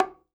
Percussion #05.wav